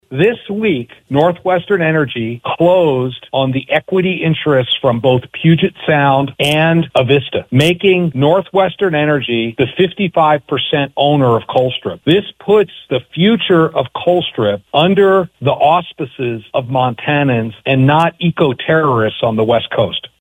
Governor Greg Gianforte was on Voices of Montana Thursday and made an announcement about what he says could be the biggest news in energy production in the last ten years in Montana.